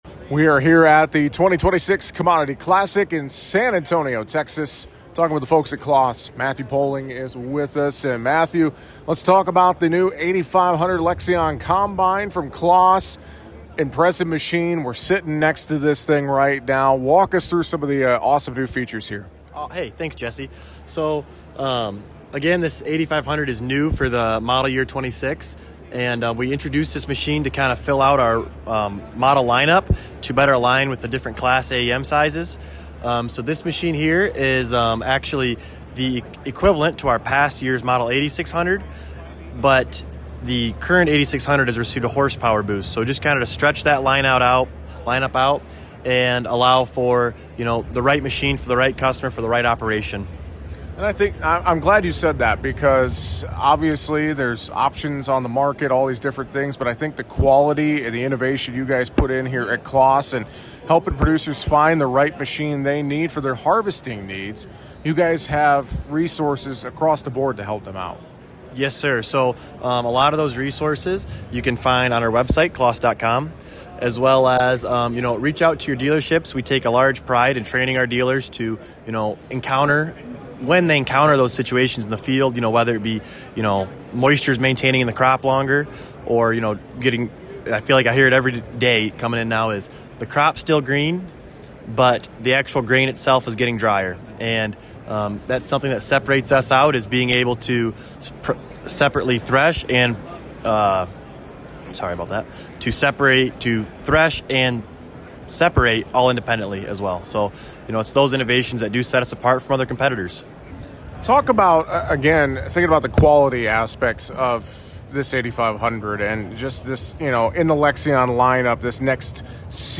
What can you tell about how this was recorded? During our time at Commodity Classic, we stopped by to visit with the team at CLAAS to look at some of their latest technology in tractors and combines.